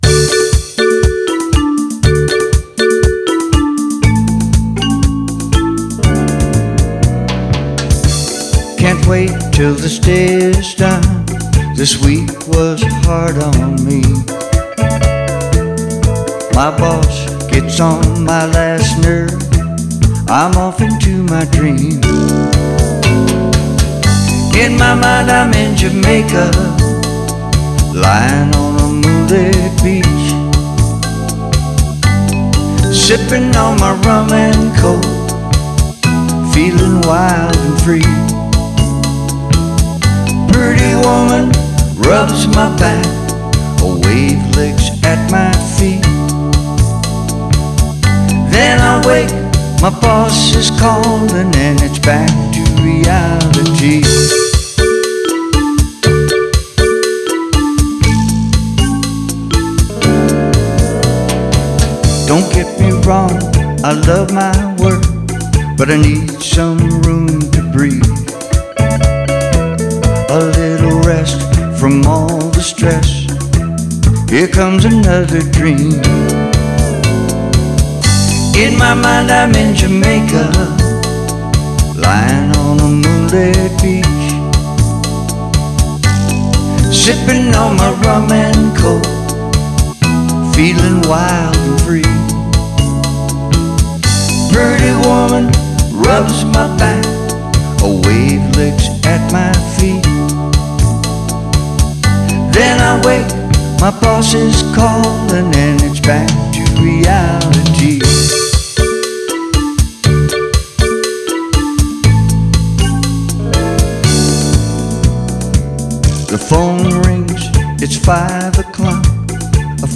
vocals, keyboards
MSA pedal steel